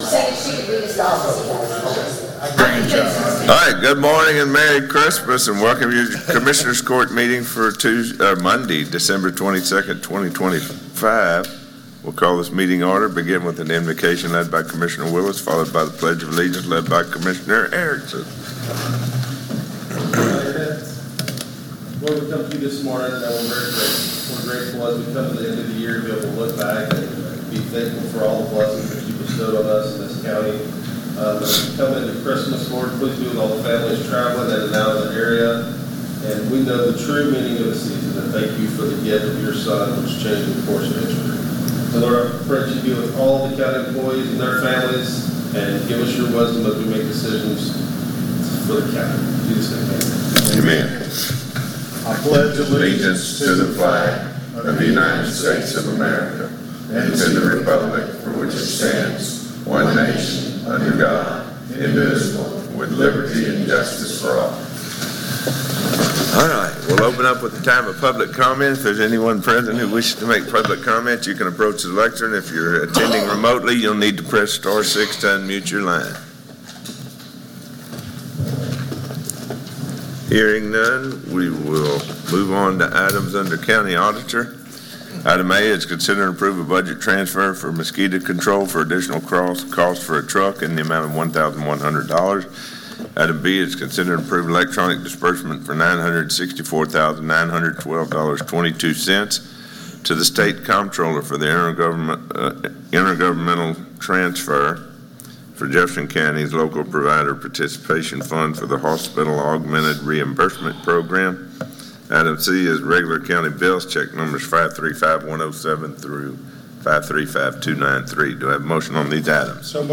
12/22/2025 Special Meeting Posted Posted